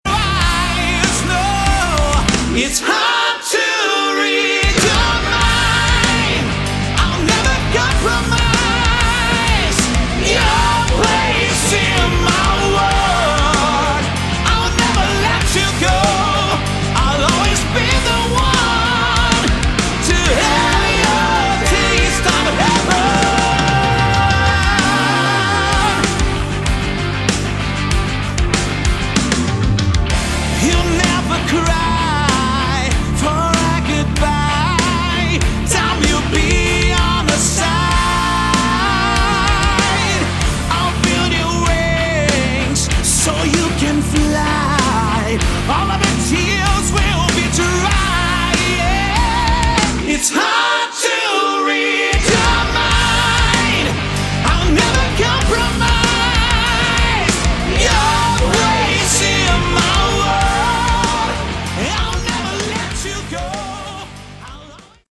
Category: Melodic Hard Rock
Guitars
Drums
Bass
Backing vocals